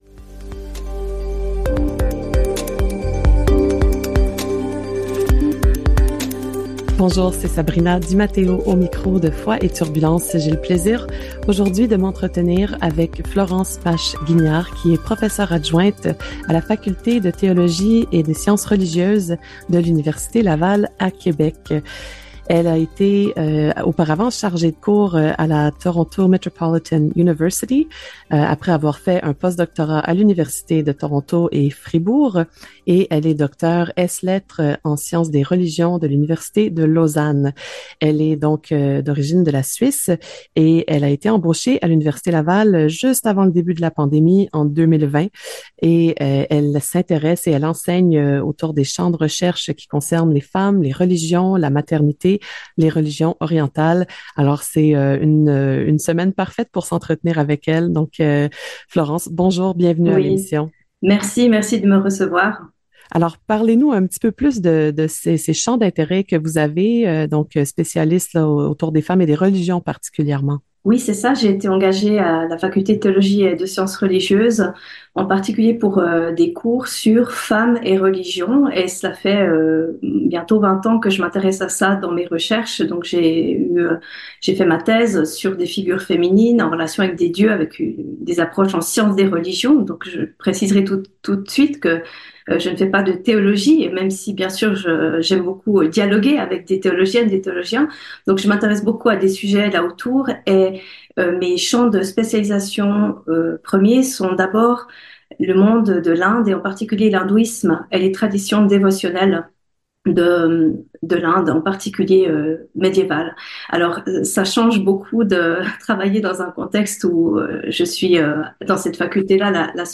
Entrevue (audio) accordée à Présence-Info, diffusée le 7 mars 2024, au sujet du cours (mai-juin 2024) et colloque (18-19 juin 2024) “ Femmes, communautés et associations religieuses au Québec: spiritualité et sororité “